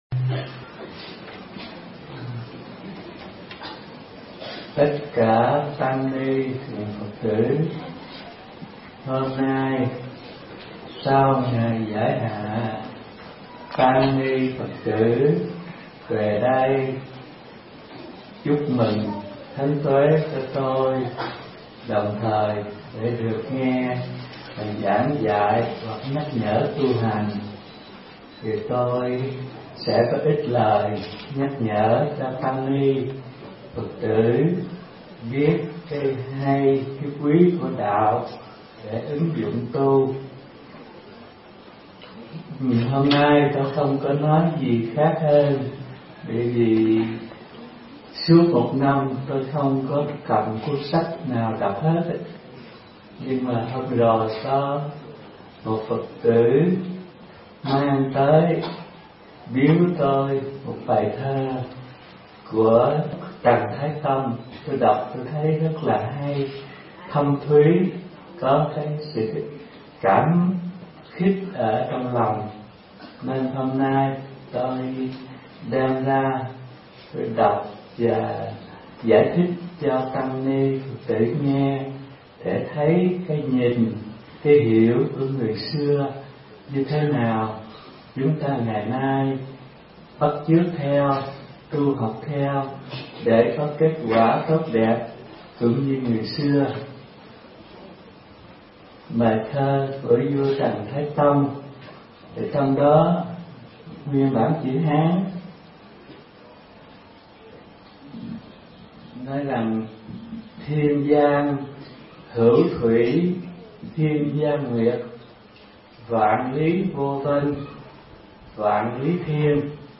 Mp3 Thuyết Pháp Trời Mây – Hòa Thượng Thích Thanh Từ